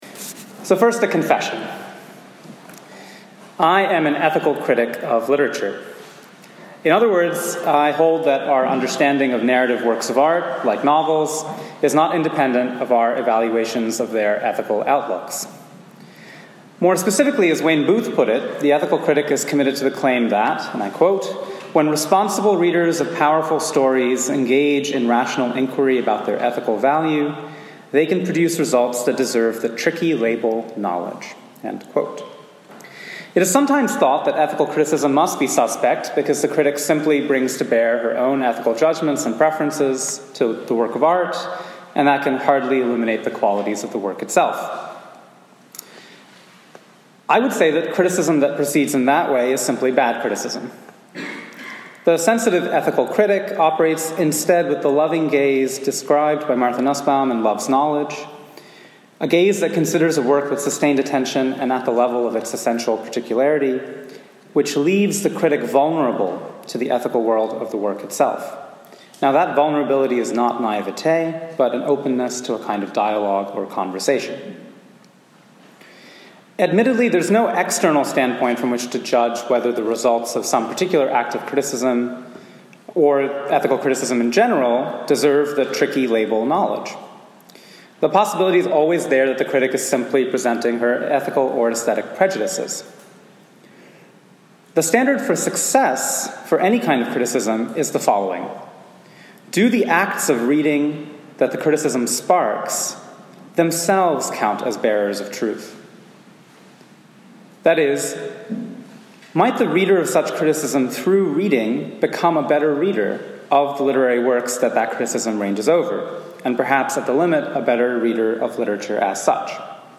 Christianity, Society & Culture, Catholic Intellectual Tradition, Catholic, Philosophy, Religion & Spirituality, Thomism, Catholicism